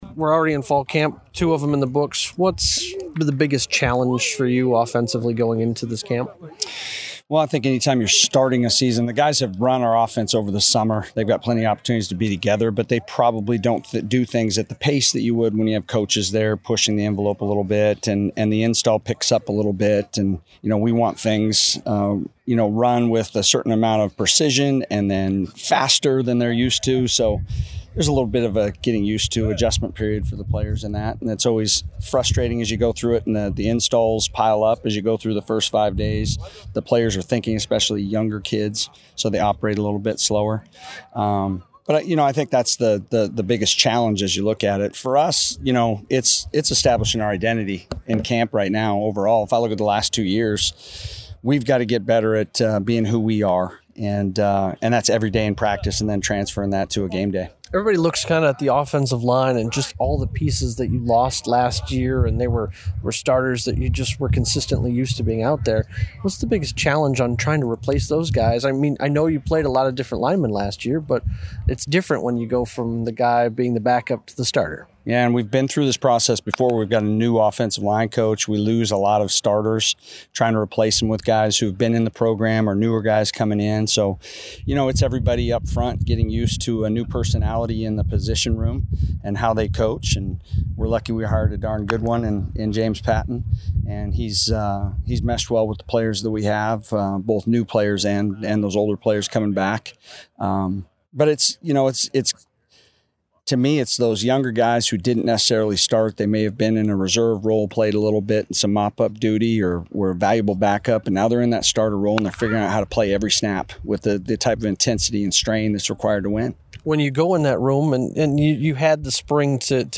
Post-Practice Audio: